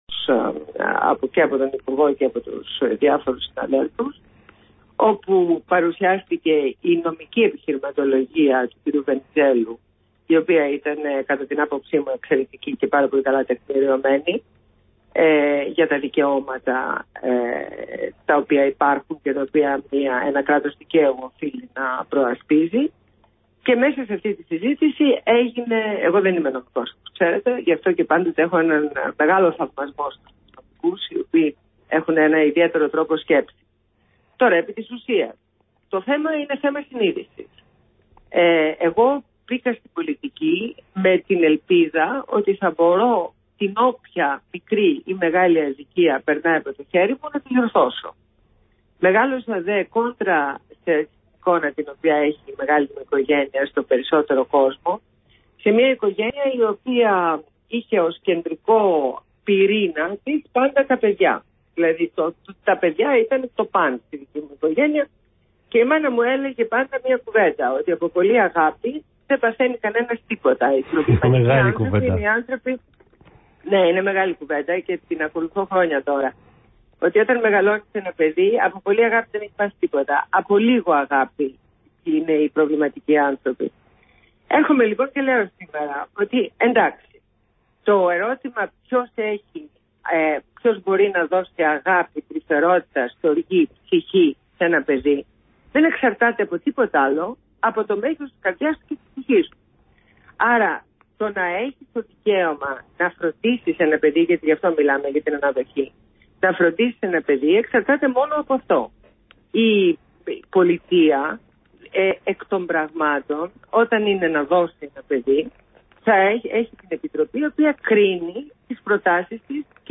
Συνέντευξη στο ραδιόφωνο NEWS247 στους 88,6 fm